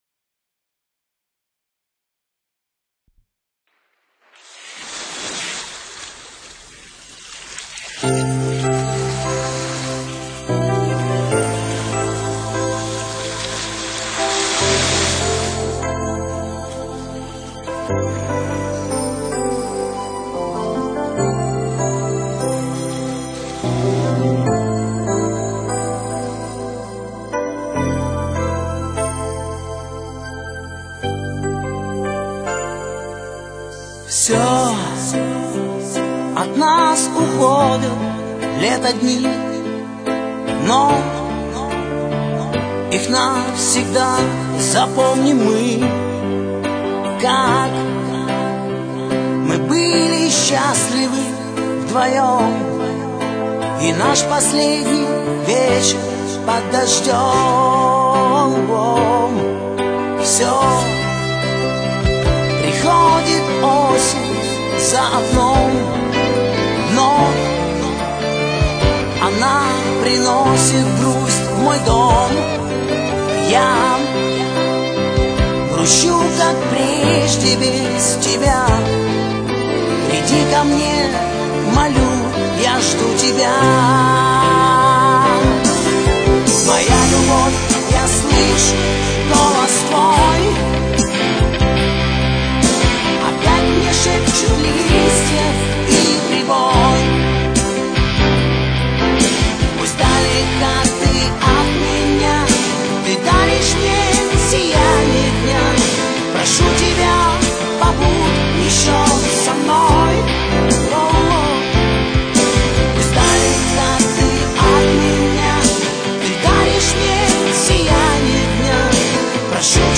гитара
саксафон